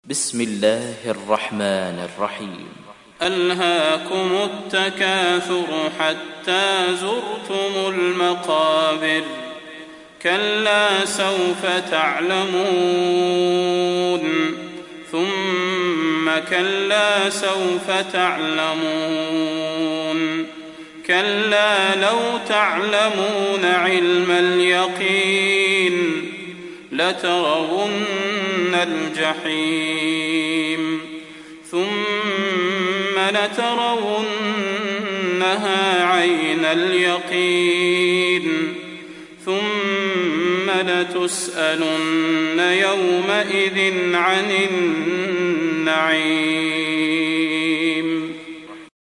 دانلود سوره التكاثر mp3 صلاح البدير روایت حفص از عاصم, قرآن را دانلود کنید و گوش کن mp3 ، لینک مستقیم کامل